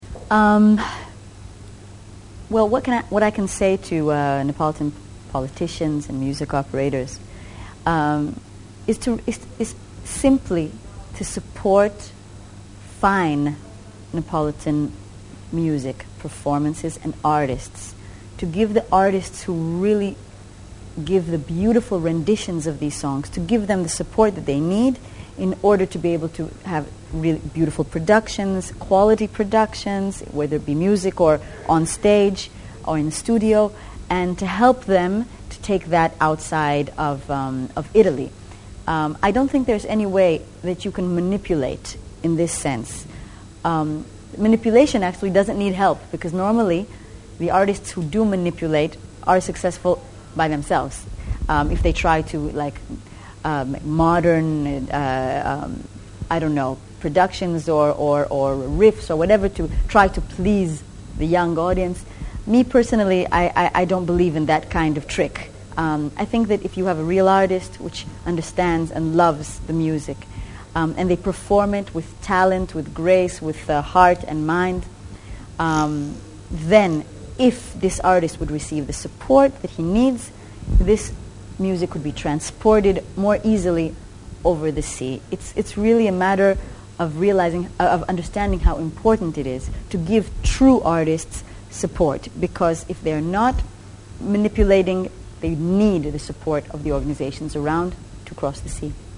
Abbiamo ritrovato nei nostri archivi un’intervista in cui Noa spiega bene il suo forte legame con Napoli.